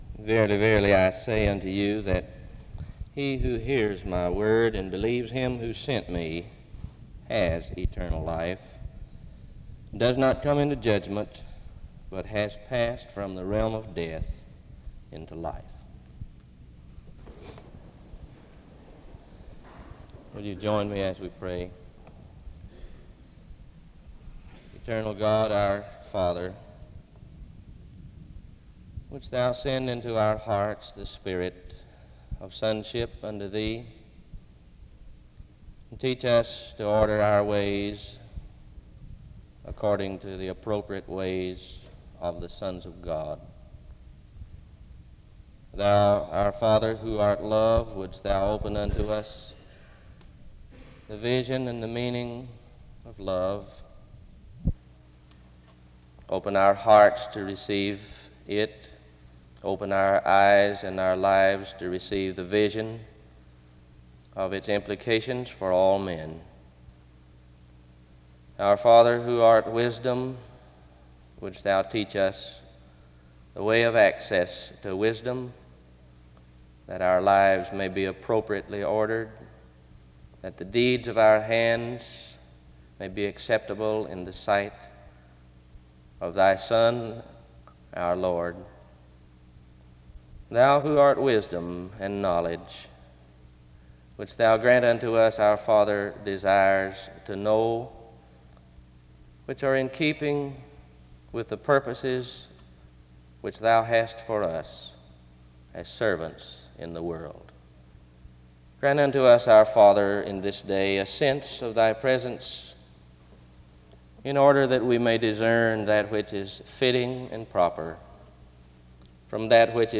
SEBTS Chapel
SEBTS Chapel and Special Event Recordings SEBTS Chapel and Special Event Recordings